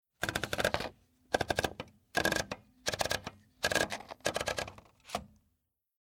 Roller knob
roller-knob.mp3